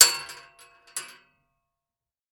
weapon
Bullet Shell 7mm Dropping On Metal 3